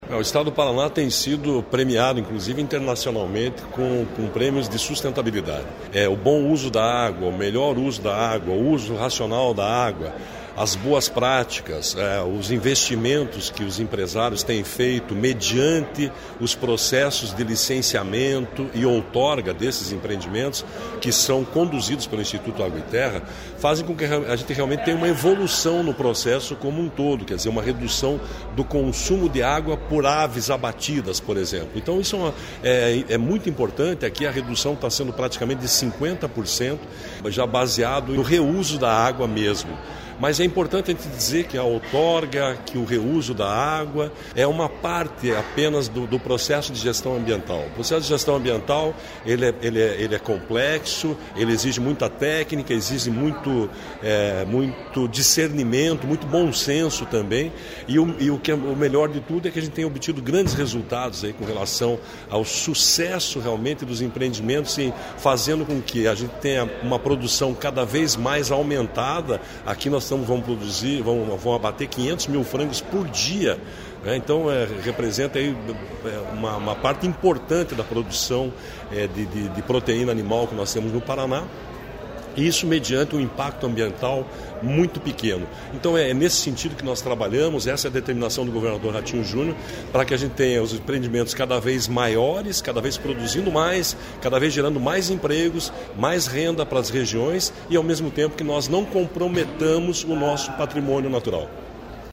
Sonora do diretor-presidente do IAT, Everton Souza, sobre a visita na sede da Lar Cooperativa Agroindustrial em Matelândia